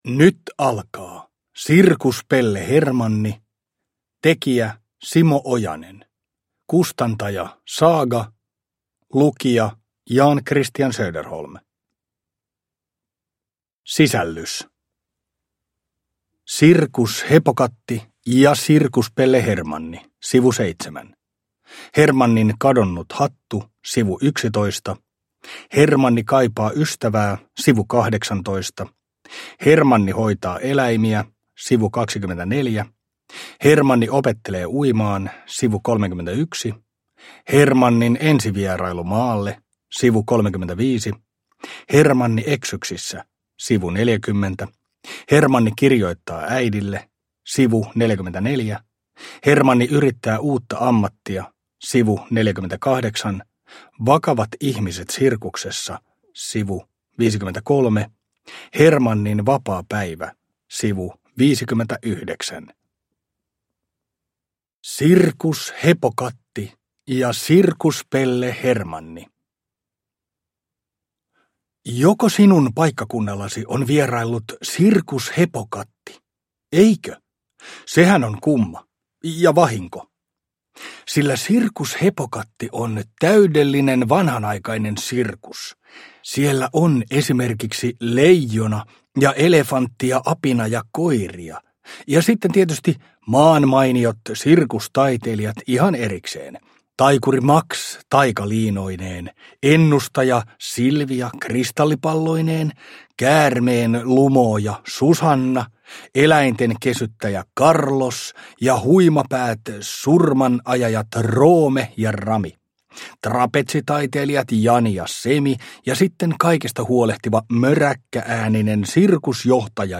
Sirkuspelle Hermanni – Ljudbok – Laddas ner